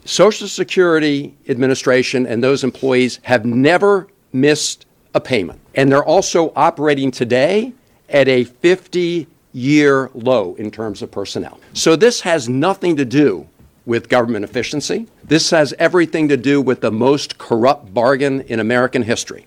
Maryland U.S. Senator Chris Van Hollen spoke in front of Capitol Hill with other Democrats to decry the Department of Government Efficiency’s move to downsize the Social Security system. Van Hollen says the cuts being made are designed to undermine confidence in the agency…